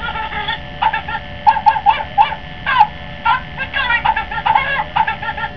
: Sound file of the Tickle-Me-Grover doll.